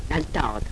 altâr, pr. altáor,